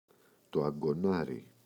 αγκωνάρι, το [aŋgoꞋnari] – ΔΠΗ